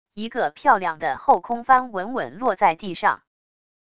一个漂亮的后空翻稳稳落在地上wav音频